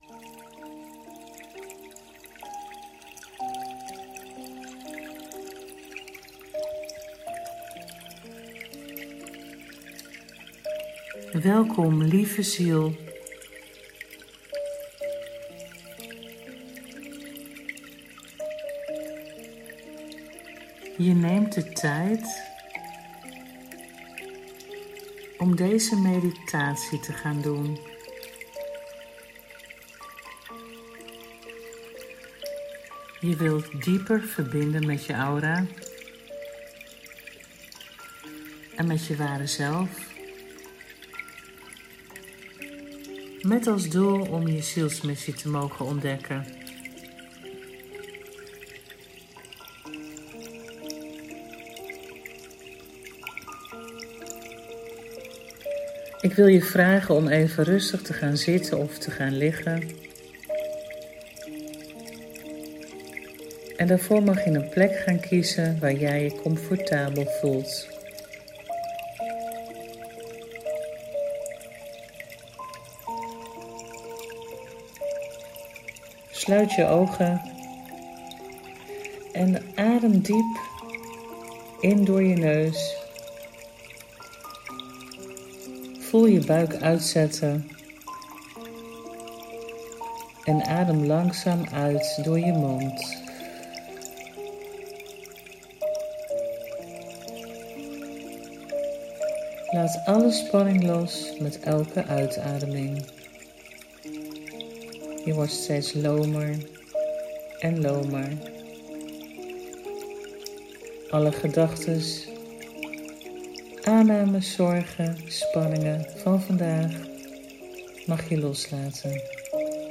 levensmissiemeditatie (1).mp3
Kom er achter wat je komt doen op aarde! Doe mijn geleide meditatie en download deze hieronder.